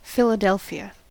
Филаде́льфия (англ. Philadelphia американское произношение: [ˌfɪləˈdɛlfiə]
En-us-Philadelphia.ogg.mp3